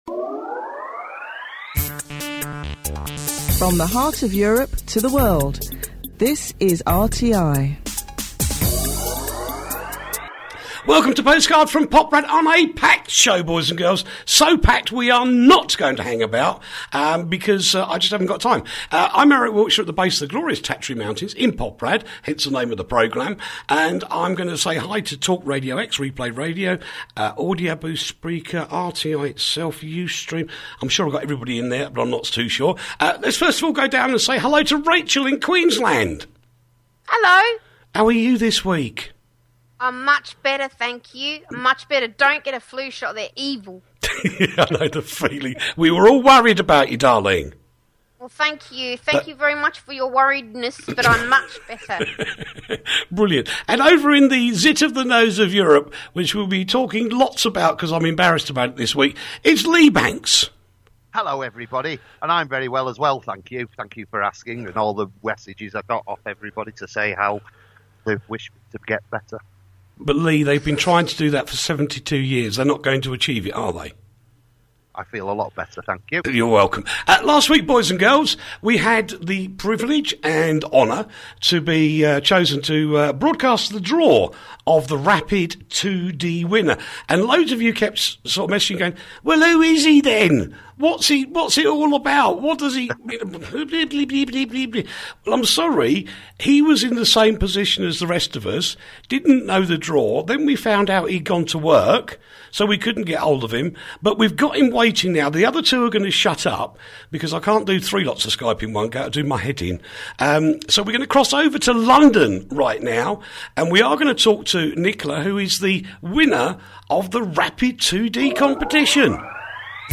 the alternative news show